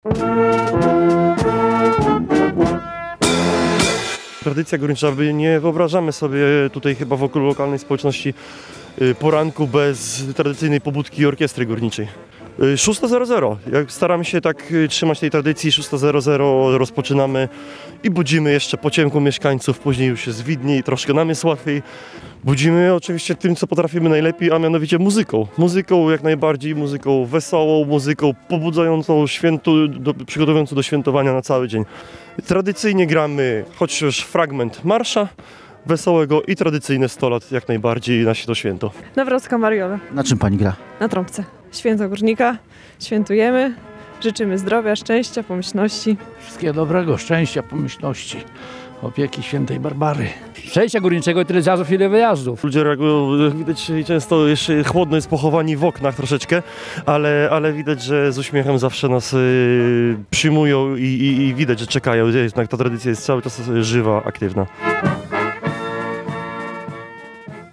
Barbórkowy poranek w Łęcznej. Tradycyjna pobudka orkiestry górniczej
W tym roku orkiestra zagrała na najstarszym osiedlu Łęcznej, które powstało wraz z budową kopalni w Bogdance.